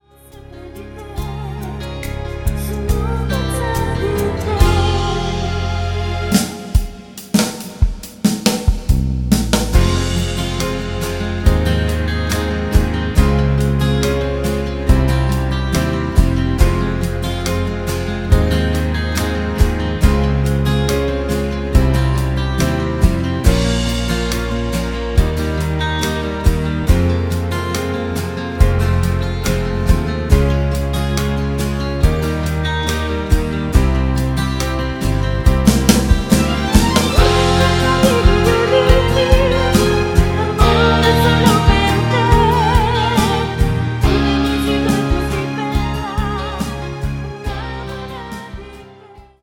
DEMO MIDI